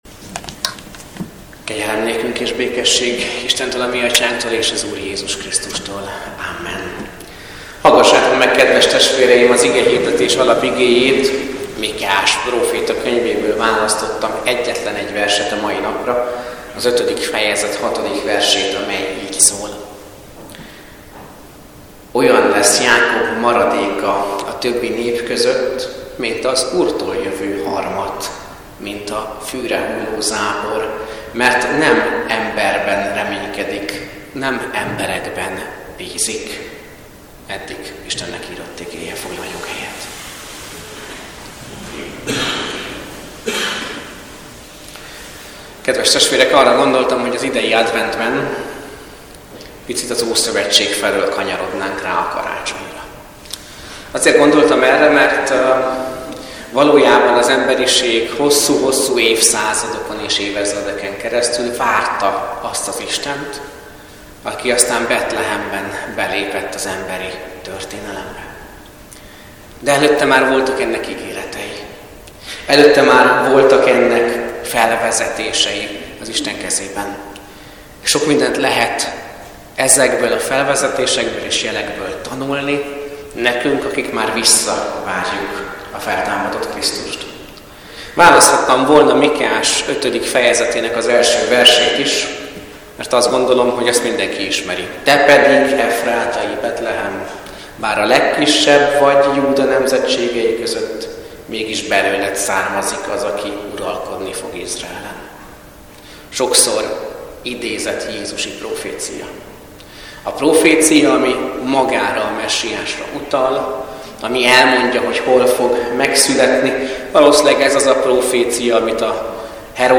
Advent esti áhítat - Ekkor így kiáltottak az Úrhoz segítségért Izrael fiai: Vétkeztünk ellened, mert elhagytuk Istenünket...